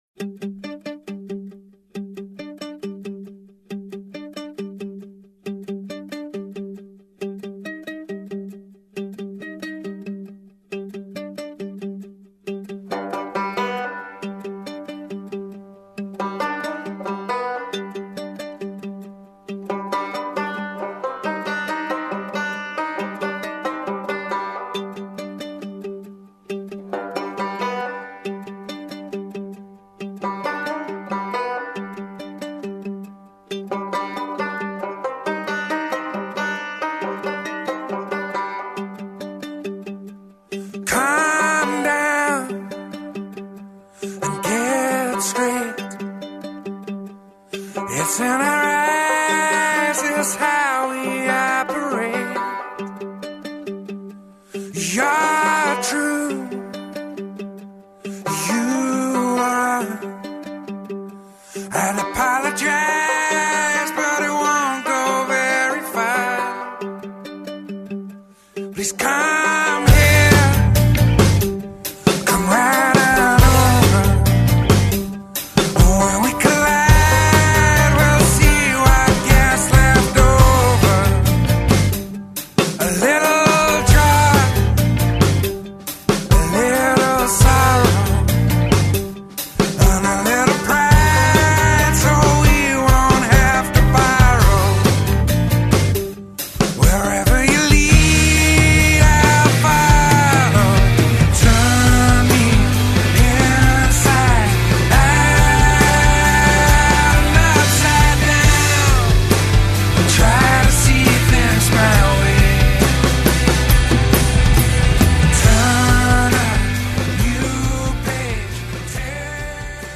album da studio